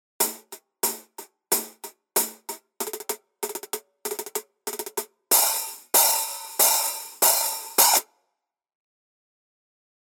Тарелки серии Custom обладают широким частотным диапазоном, теплым плотным звуком и выдающейся музыкальностью.
Masterwork 10 Custom Hats sample
Custom-Hihat-10.mp3